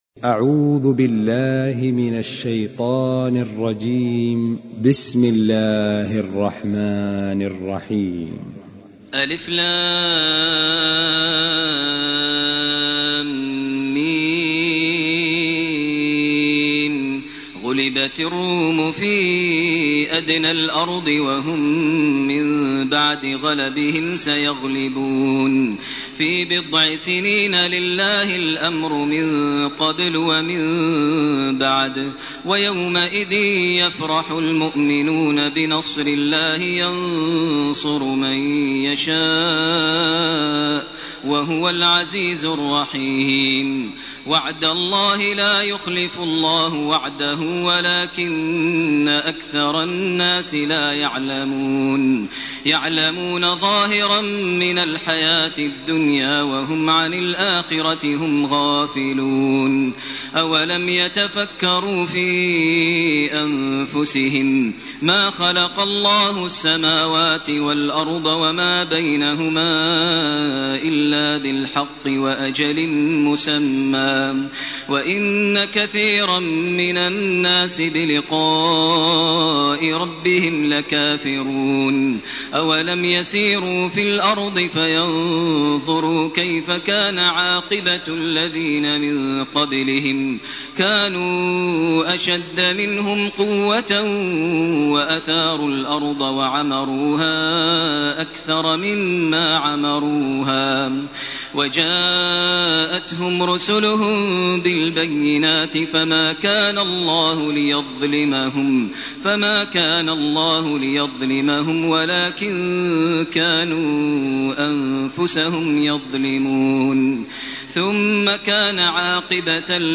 سورة الروم وسورة لقمان > تراويح ١٤٢٨ > التراويح - تلاوات ماهر المعيقلي